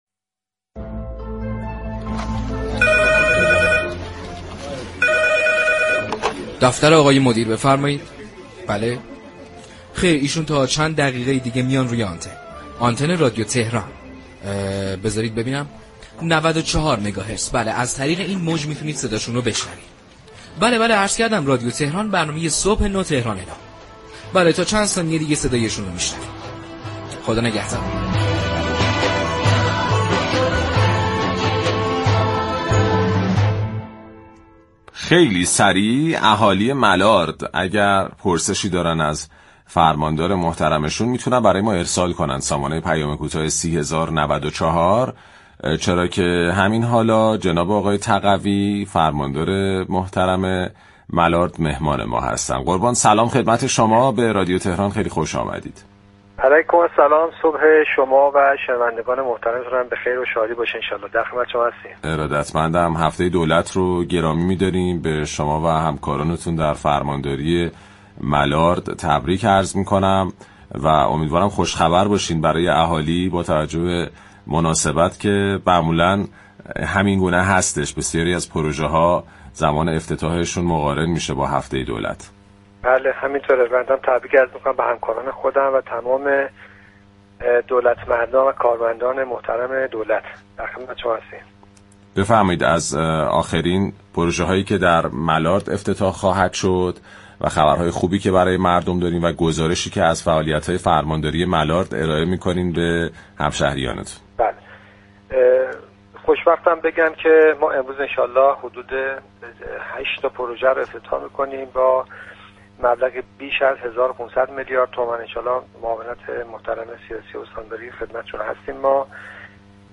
در مصاحبه تلفنی با برنامه «صبح نو، تهران نو» رادیو تهران